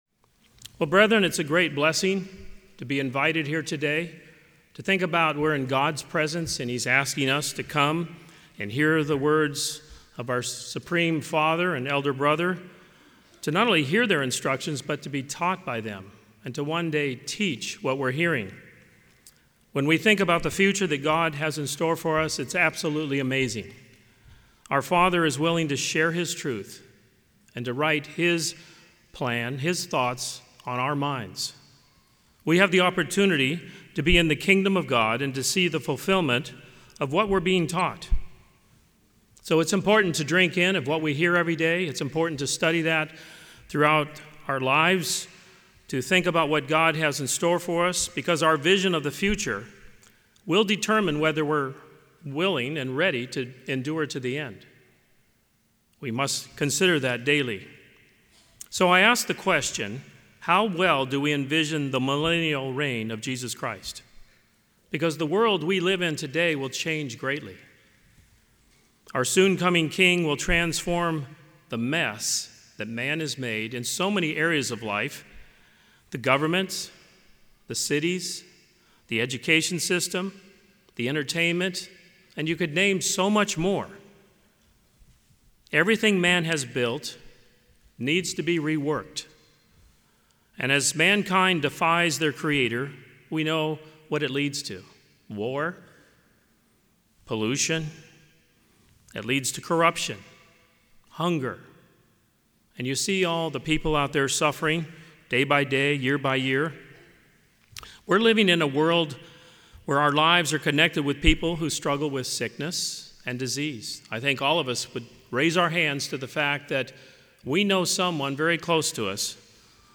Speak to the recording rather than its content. This sermon was given at the Jekyll Island, Georgia 2023 Feast site.